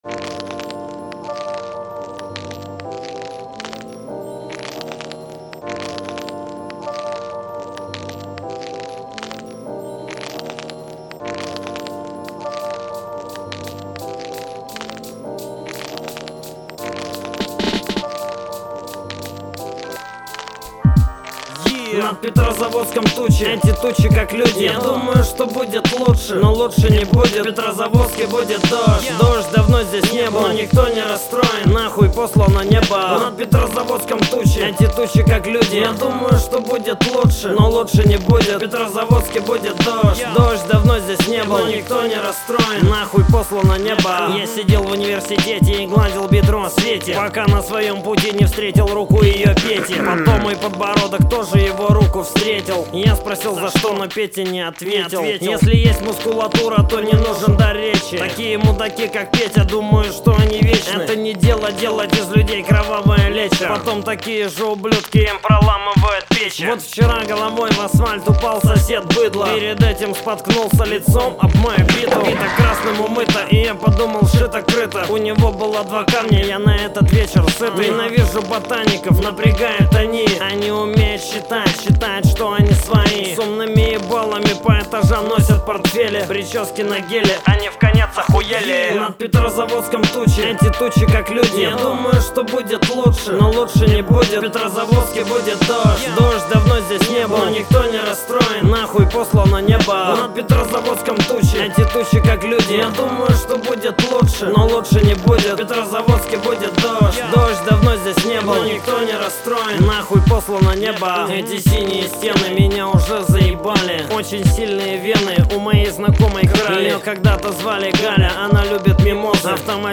Категория: Русский рэп, хип-хоп